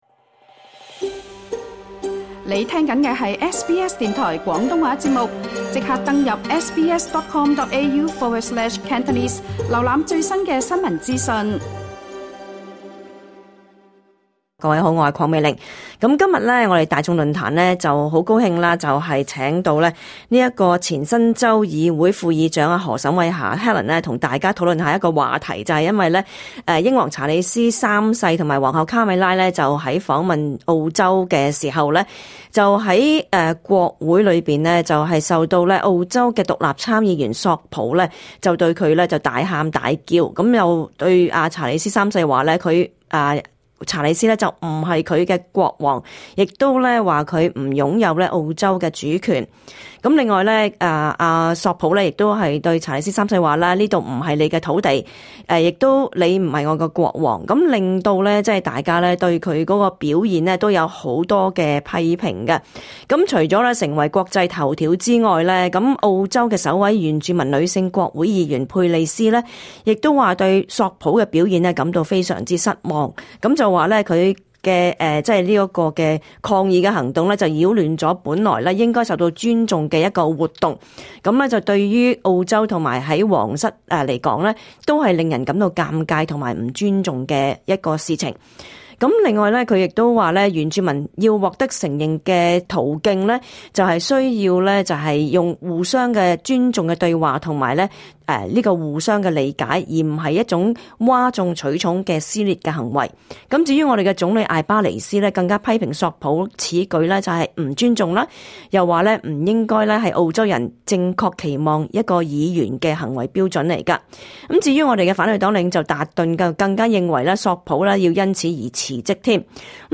我們亦很高興邀請到前新州議會副議長何沈慧霞和大家一起討論這個話題。